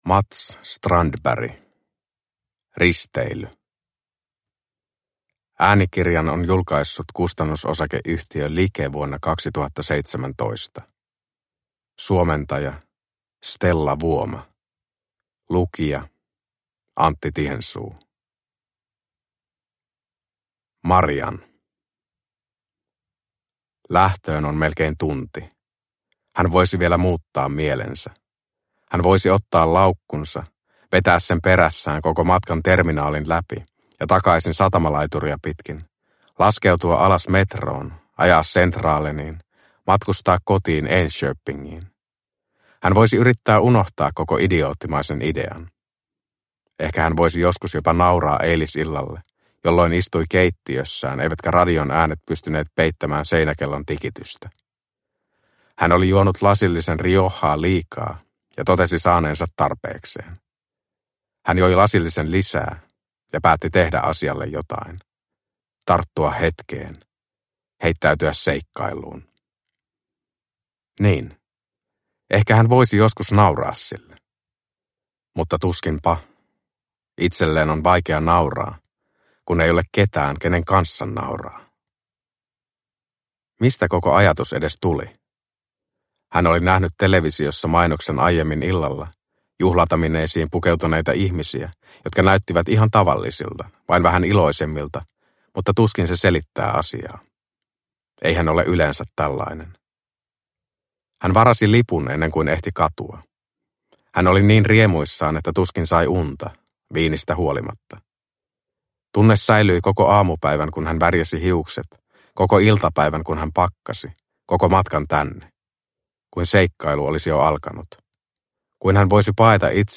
Risteily – Ljudbok – Laddas ner